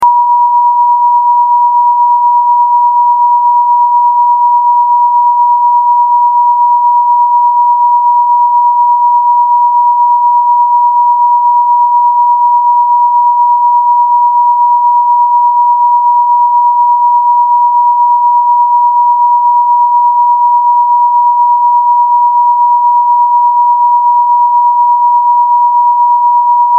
963Hz Solfeggio, known as the sound effects free download